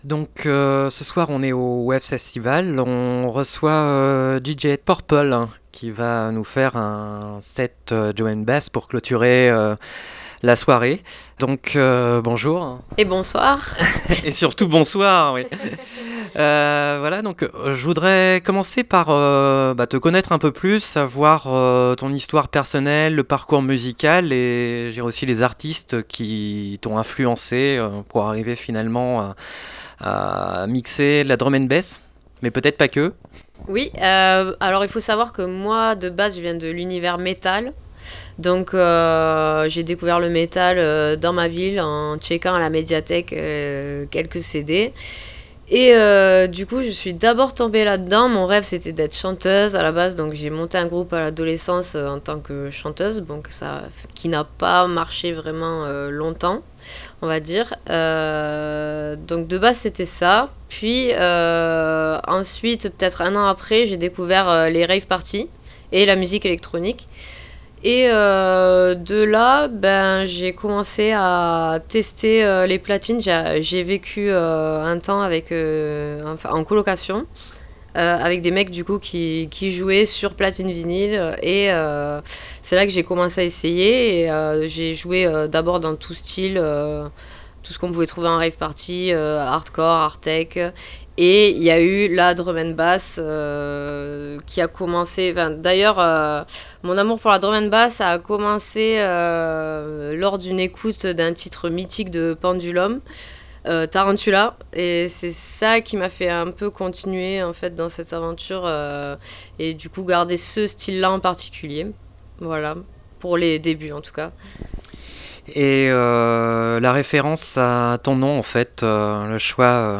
Ce samedi 1er février, BLP Radio était présent à la 2e édition du Waves Festival à Palaiseau.